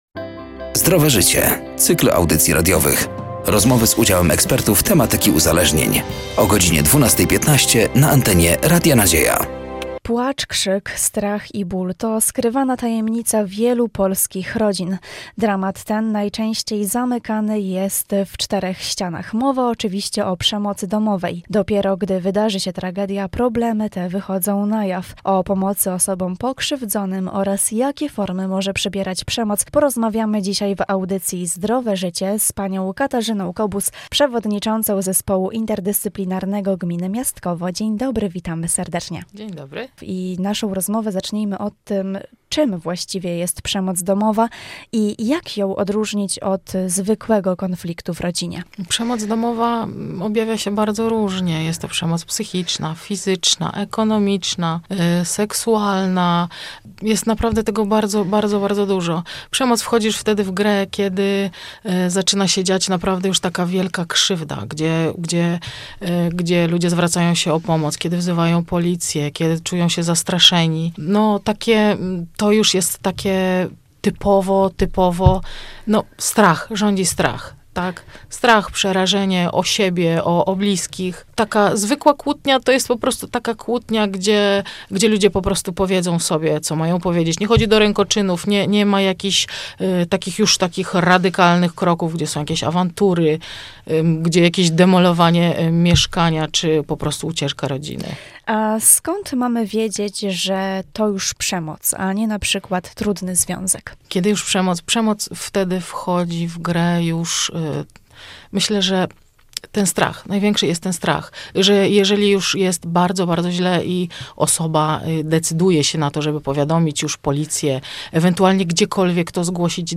„Zdrowe Życie” to cykl audycji radiowych. Rozmowy z udziałem ekspertów tematyki uzależnień.